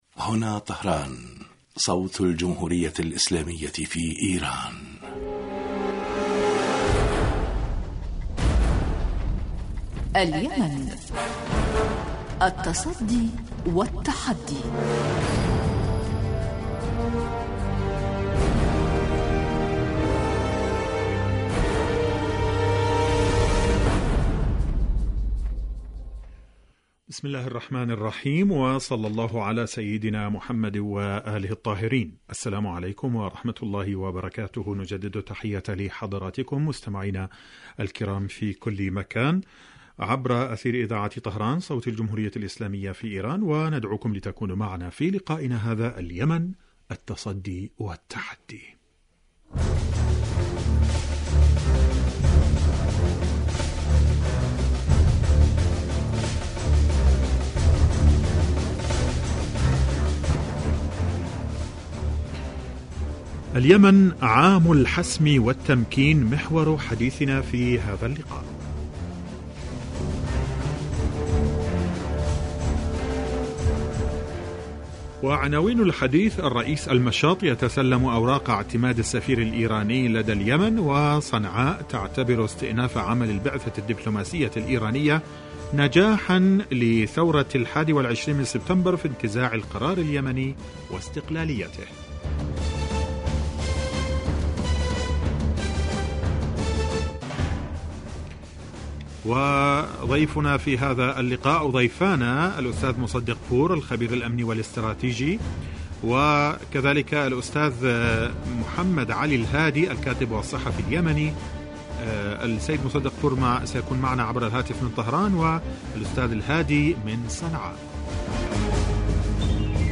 برنامج سياسي حواري يأتيكم مساء كل يوم من إذاعة طهران صوت الجمهورية الإسلامية في ايران .
البرنامج يتناول بالدراسة والتحليل آخر مستجدات العدوان السعودي الأمريكي على الشعب اليمني بحضور محللين و باحثين في الاستوديو